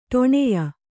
♪ ṭoṇeya